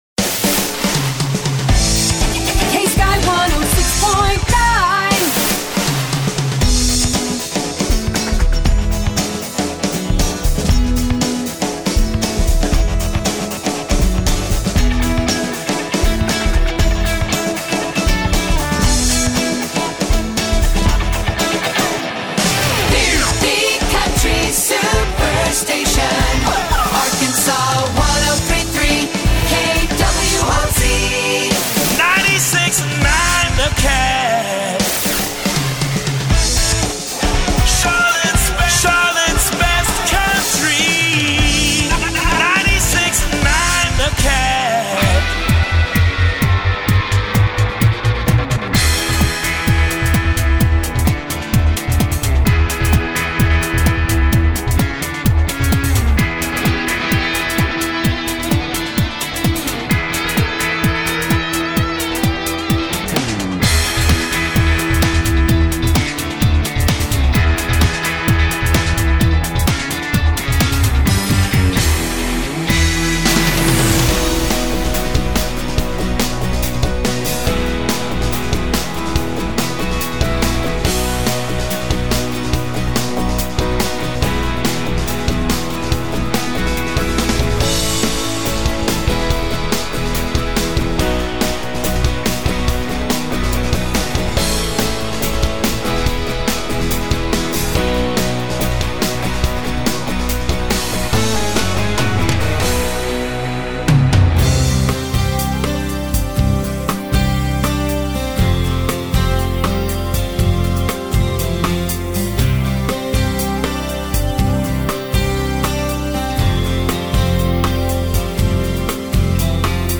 Take a listen to the montage of the updates bellow: